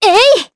Leo-Vox_Attack1_jp.wav